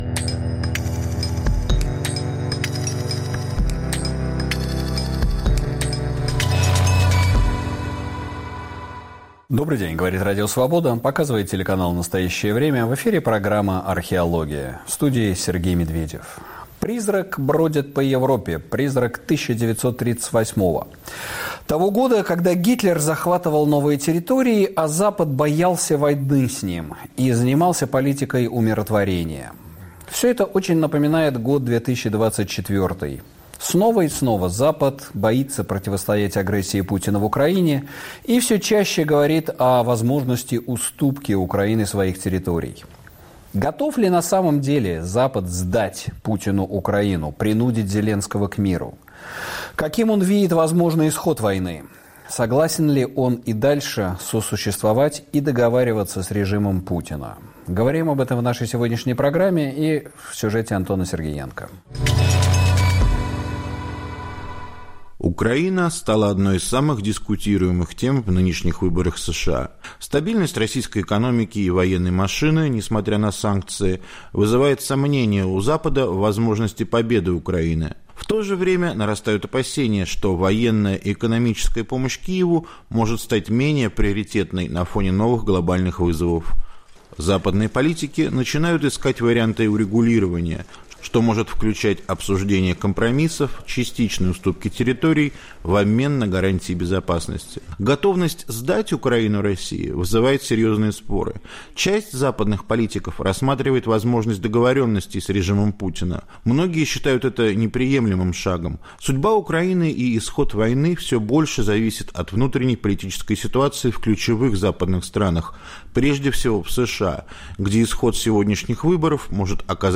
экономист и политолог
военный эксперт